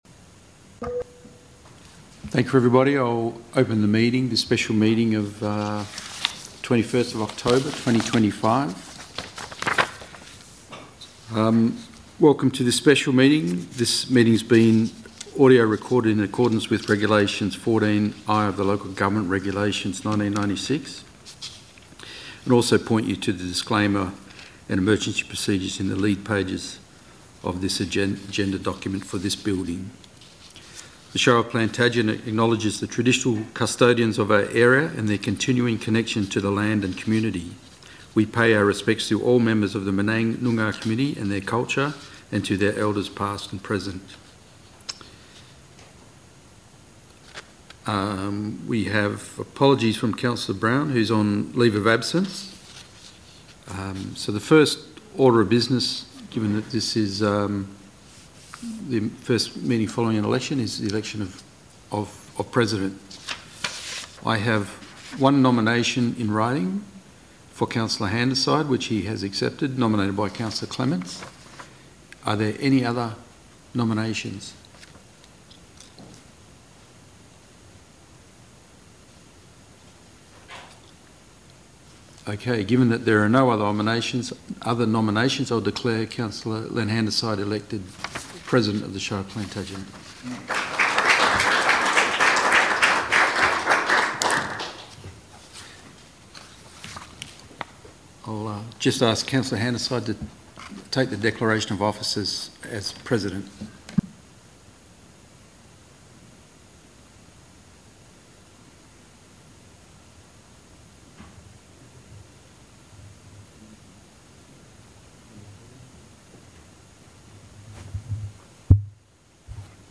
Special Meeting of Council - Tuesday 21 October 2025 - 5pm - Post Election Actions » Shire of Plantagenet